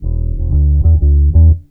BASS 28.wav